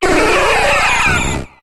Cri de Mewtwo dans Pokémon HOME.